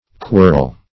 Definition of quirl.
quirl - definition of quirl - synonyms, pronunciation, spelling from Free Dictionary Search Result for " quirl" : The Collaborative International Dictionary of English v.0.48: Quirl \Quirl\ (kw[~e]rl), n. & v. See Querl .